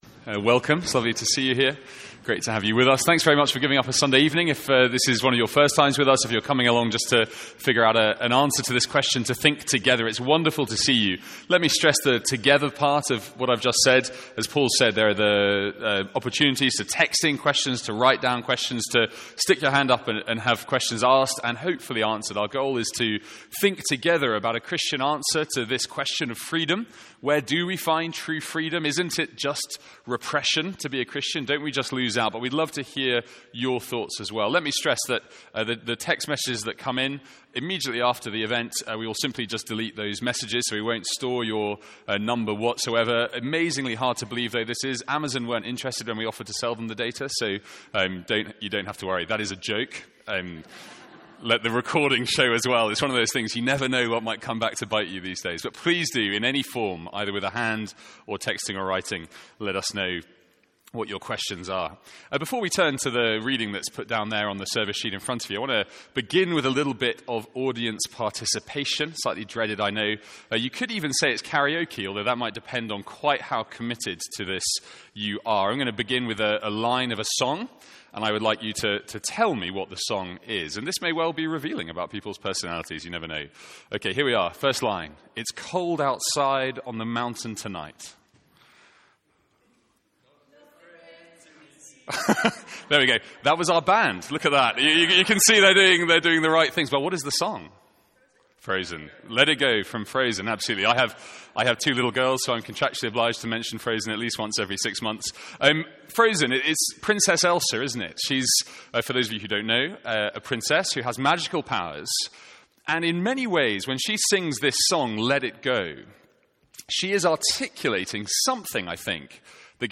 Sermons | St Andrews Free Church
From our evening guest event series 'If you could ask God one question...'